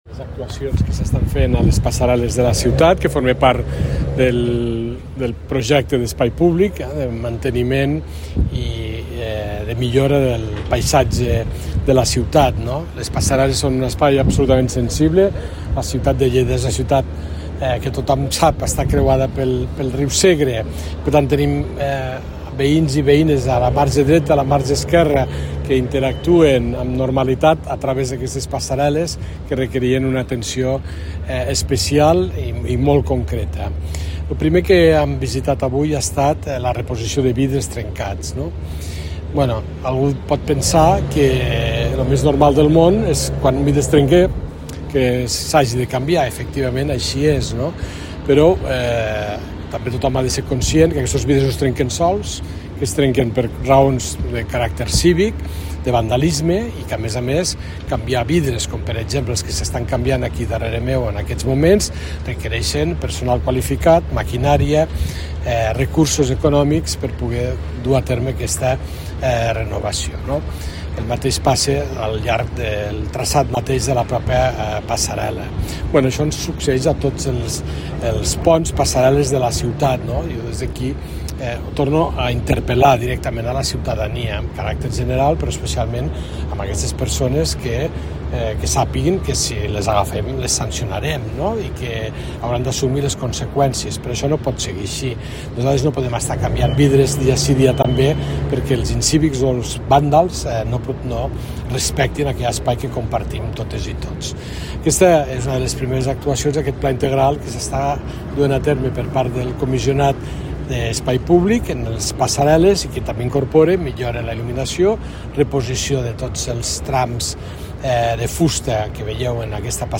Talls de veu
Tall de veu del paer en cap, Fèlix Larrosa